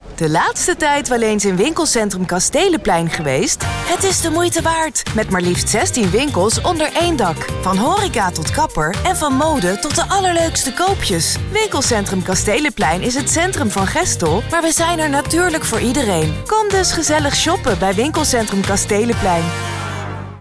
Promos
I am a professional Dutch Voice-over with a clear, warm and fresh voice.